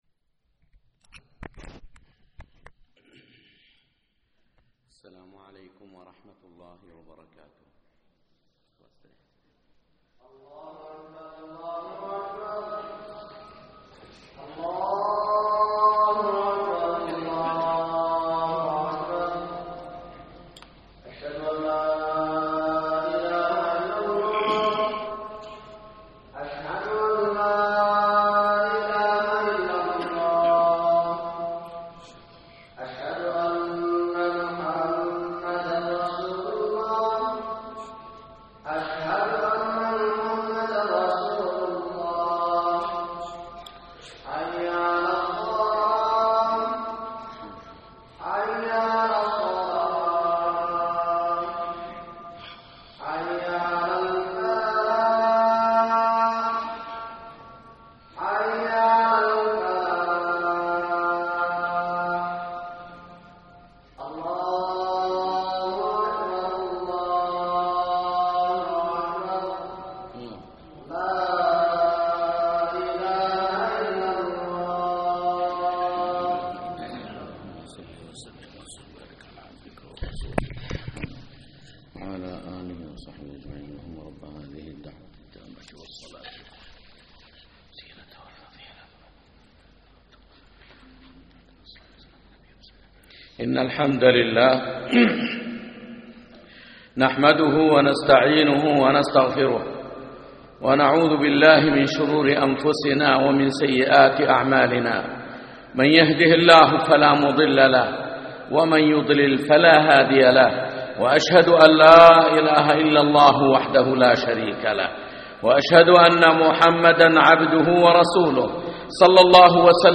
خطبة اتق الله حيثما كنت